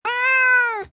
Animal Sounds Soundboard2,171 views